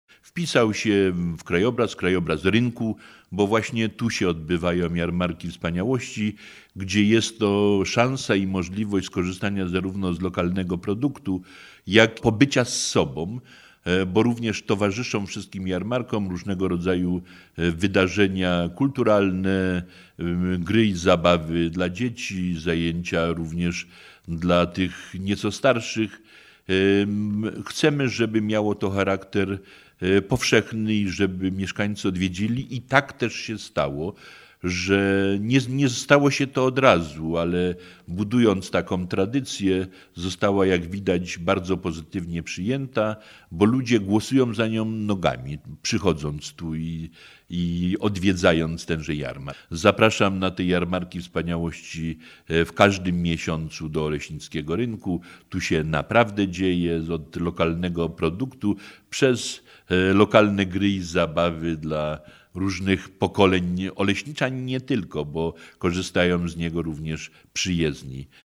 – Jarmark Wspaniałości to już nasza tradycja – mówi Jan Bronś, Burmistrz Oleśnicy.